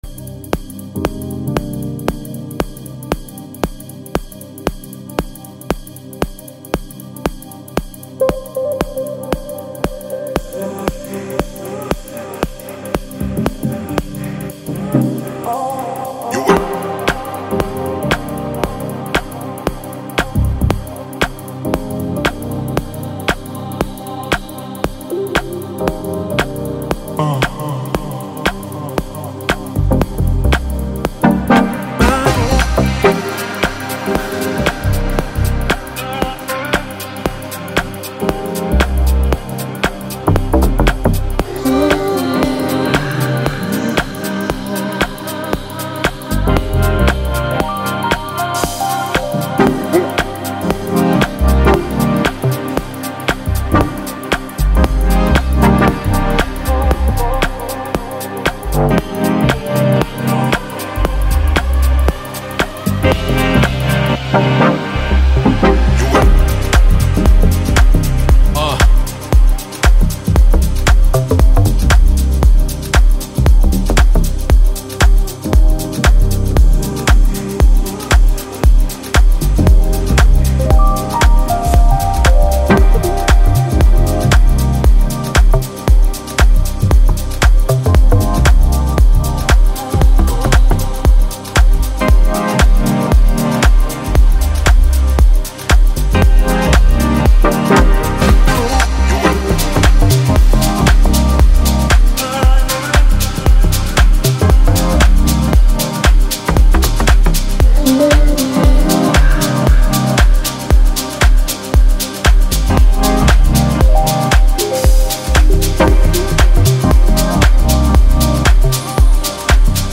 soul-stirring song